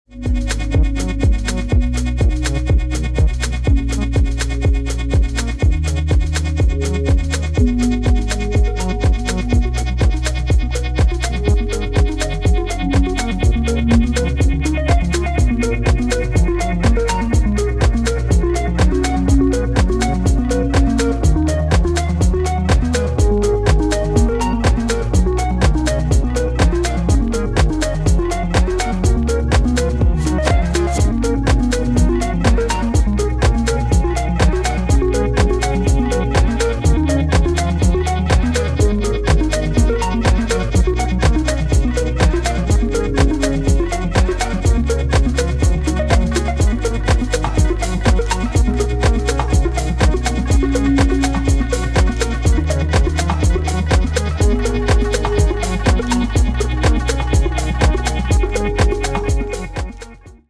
浮遊感や中毒性、温かみを備えたナイスな一枚に仕上がっています。